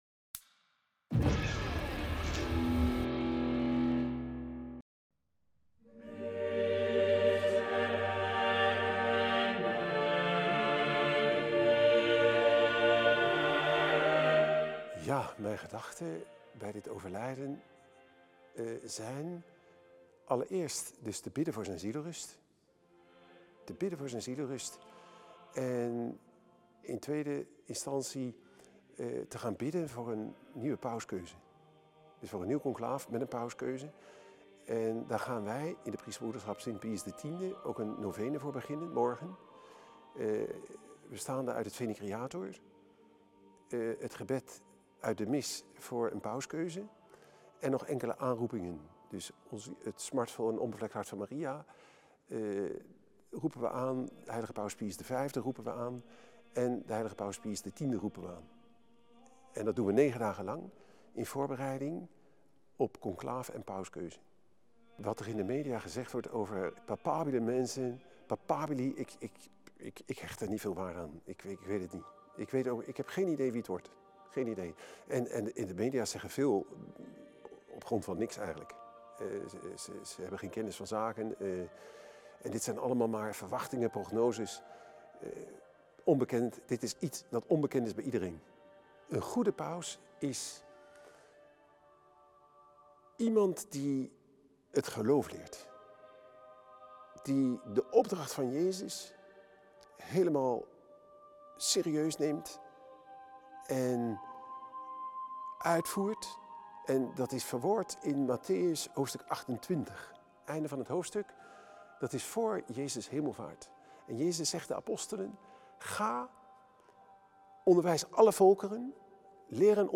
MINIDOCU: De nieuwe paus